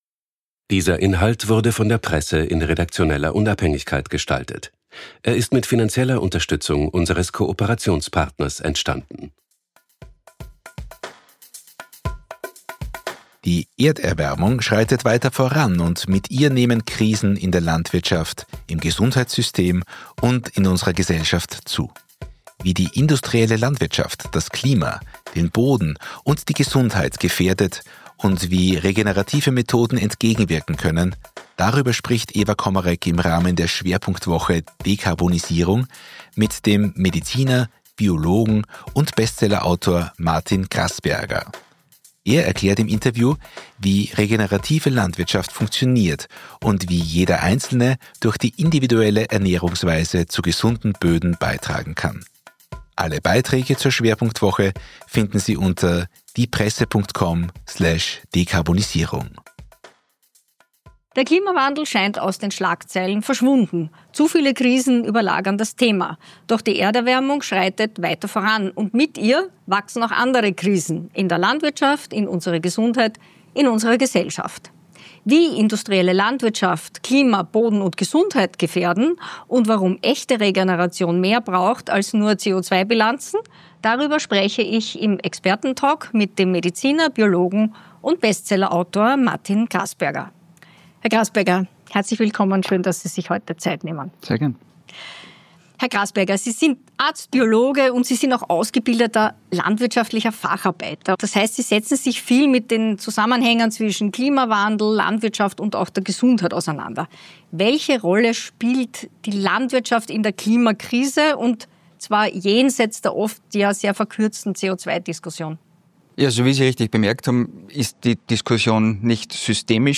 Um nachhaltig Lebensmittel zu produzieren, sind gesunde Böden wichtig. Im Gespräch mit der „Presse“ erläutert Mediziner